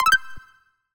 Universal UI SFX / Basic Menu Navigation
Menu_Navigation01_Open.wav